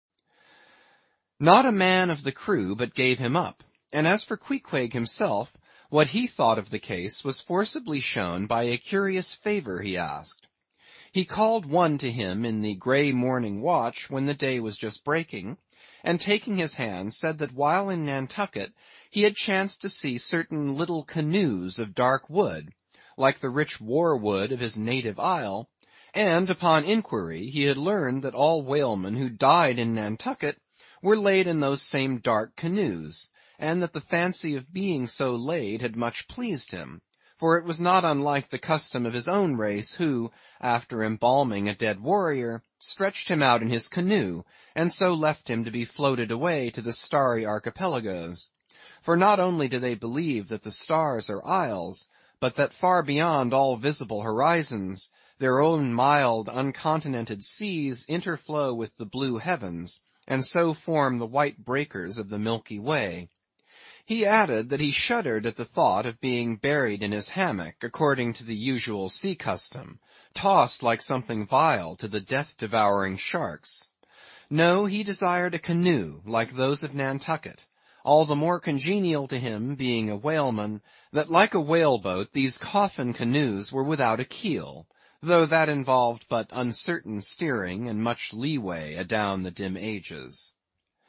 英语听书《白鲸记》第904期 听力文件下载—在线英语听力室